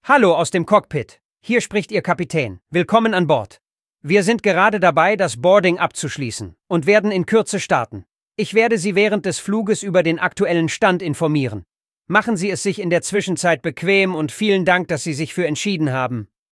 BoardingWelcomePilot.ogg